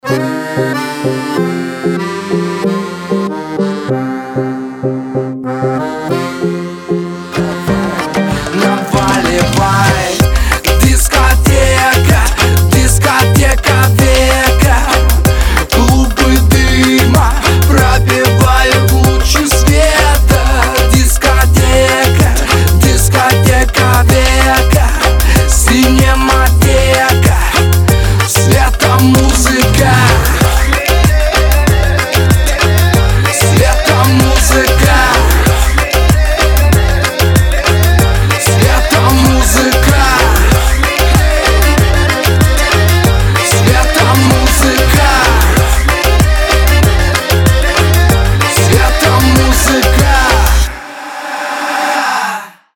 • Качество: 320, Stereo
поп
dance